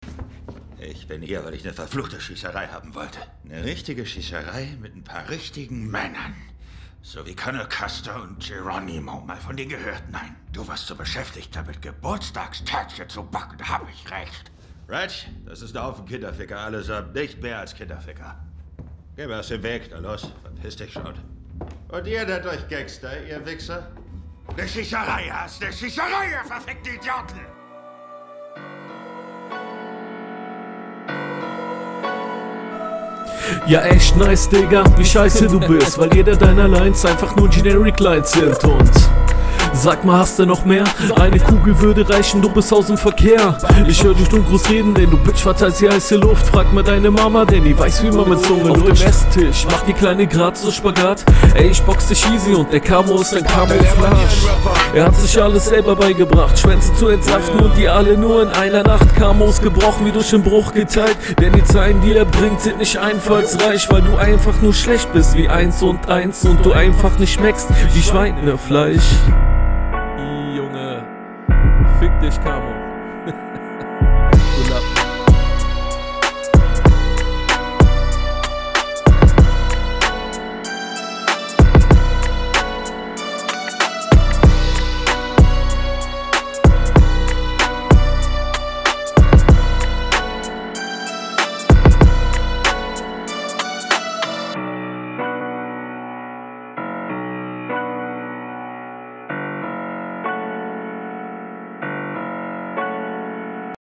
Flow ganz stabil einige minime Flowfehler drin und am Schluss war die Line komisch geflowt …